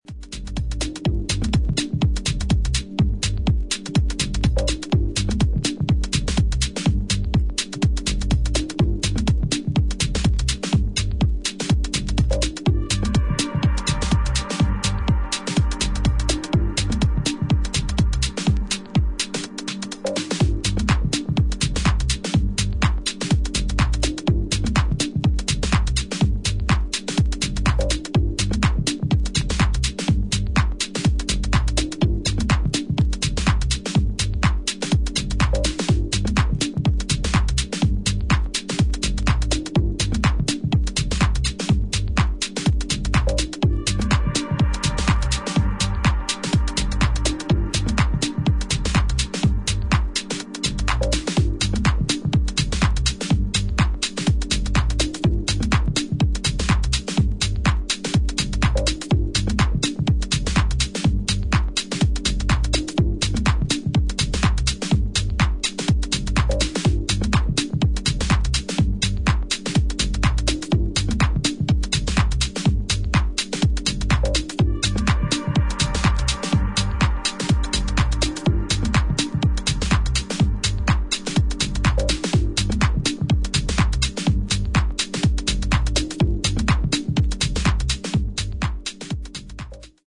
グルーヴを丁寧に刻んでいくテックハウス全4曲を収録